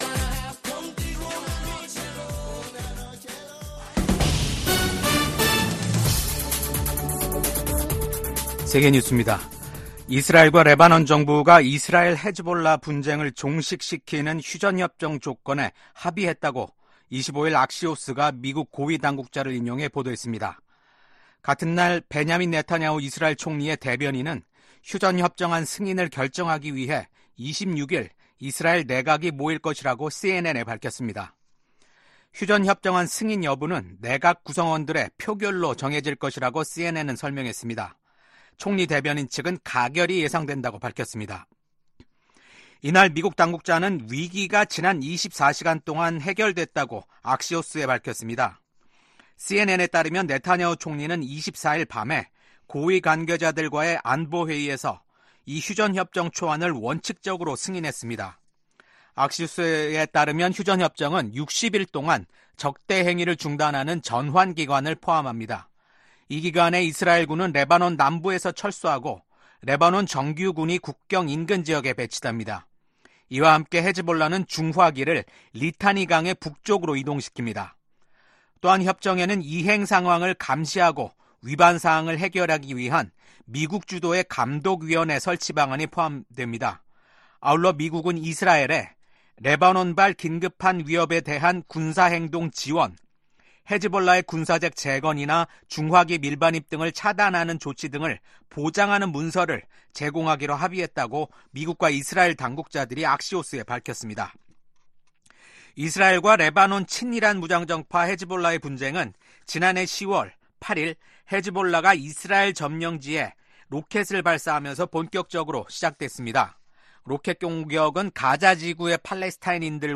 VOA 한국어 아침 뉴스 프로그램 '워싱턴 뉴스 광장'입니다. 러시아 고위 당국자가 한국이 우크라이나에 살상무기를 공급하면 강력 대응하겠다고 경고했습니다. 미국 백악관 고위 관리는 북한과 러시아의 군사적 관계 강화가 차기 행정부에도 쉽지 않은 도전이 될 것이라고 전망했습니다. 미국은 북한이 7차 핵실험 준비를 마치고 정치적 결단만 기다리고 있는 것으로 평가하고 있다고 국무부 당국자가 말했습니다.